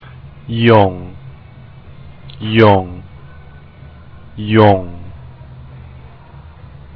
Pronunciation of Forever (It is pronounced 3 times)